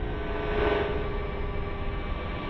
电台提示音循环 " beeploop01
描述：由短波数据传输而成的循环。
标签： 蜂鸣声 循环 无线电 短波
声道立体声